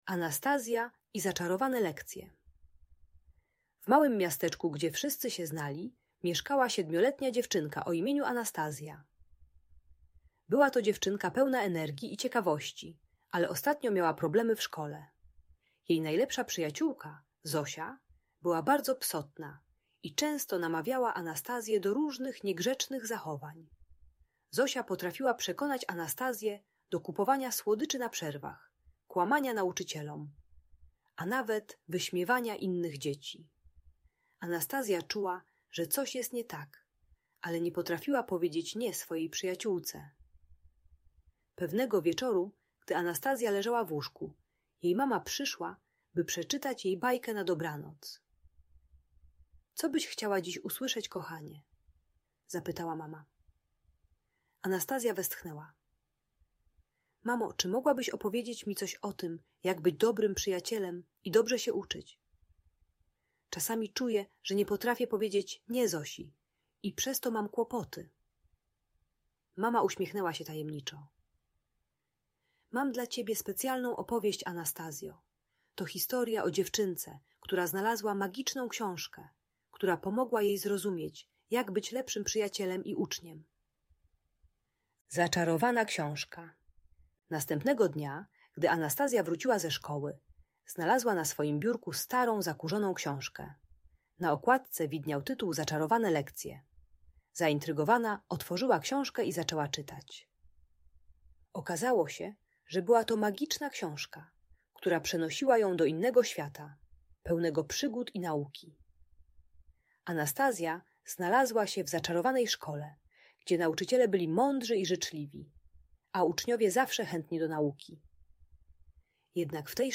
Anastazja i Zaczarowane Lekcje - Szkoła | Audiobajka